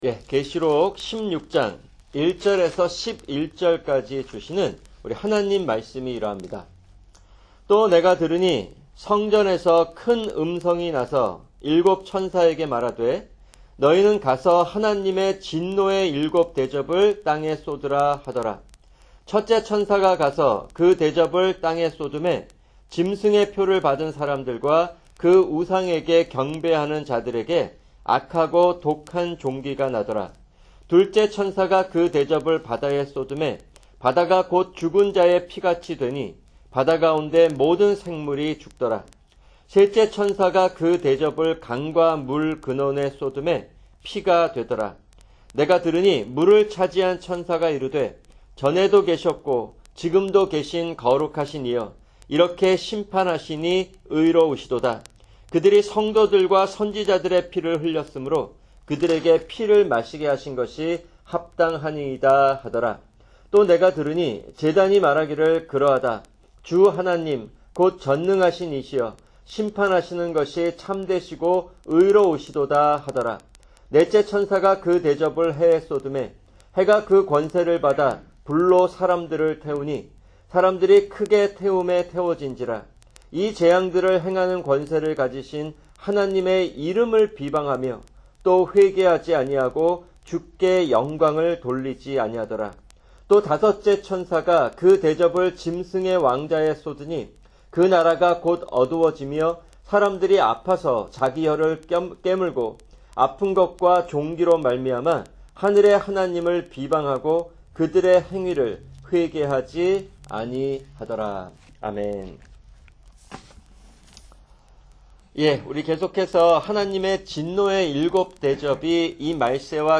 [주일 설교] 학개(8) 2:10-19(2)